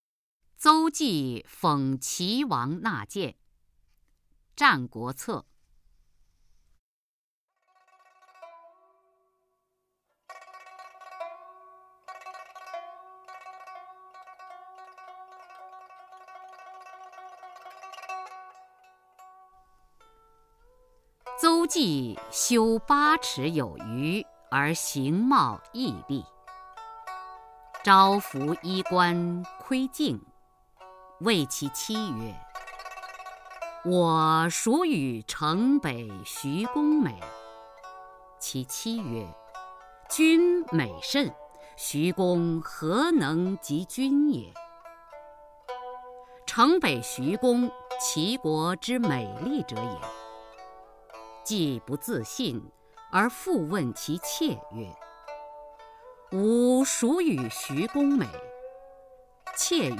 初中生必背古诗文标准朗诵（修订版）（1）-05-雅坤-邹忌讽齐王纳谏 《战国策 齐策》西汉 刘向